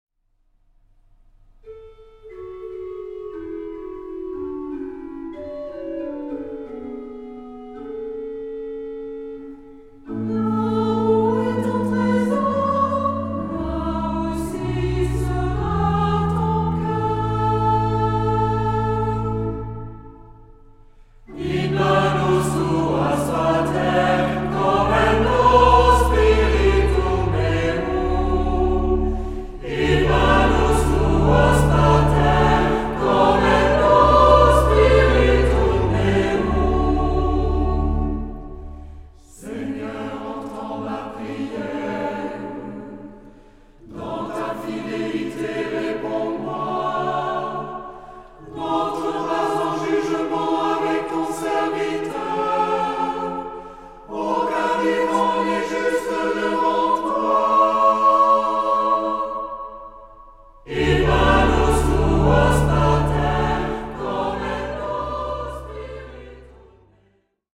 Genre-Stil-Form: Tropar ; Psalmodie
Charakter des Stückes: andächtig
Chorgattung: SAH  (3 gemischter Chor Stimmen )
Instrumente: Orgel (1) ; Melodieinstrument (ad lib)
Tonart(en): A-Dur ; D-Dur
Liturgischer Bezug: ; Eingangslied ; Jahreskreis